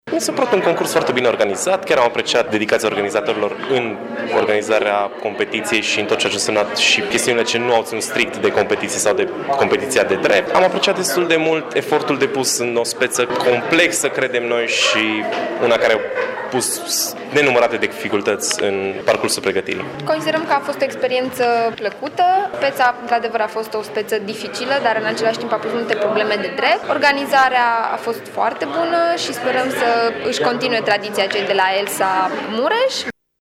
Reprezentanţii echipelor finaliste au apreciat organizarea evenimentului, dar spun că speţa dedusă judecăţii nu a fost deloc uşoară: